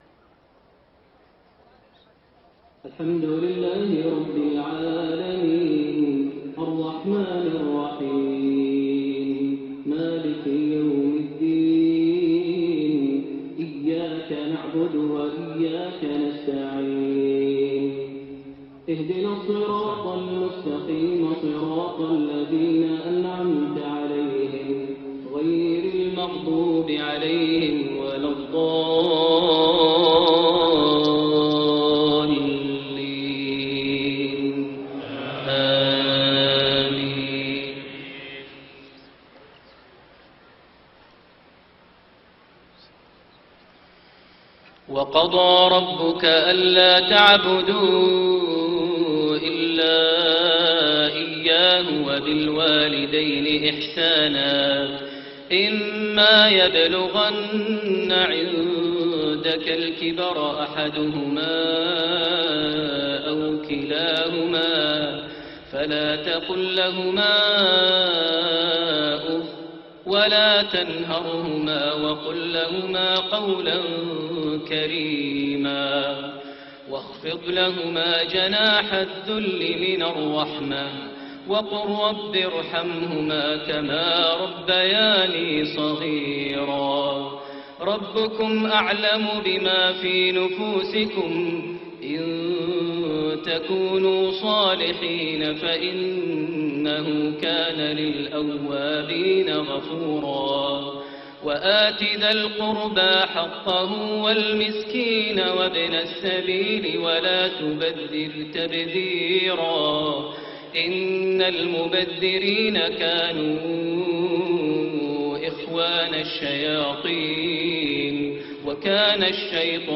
صلاة العشاء5-4-1432 من سورة الإسراء 23-39 > 1432 هـ > الفروض - تلاوات ماهر المعيقلي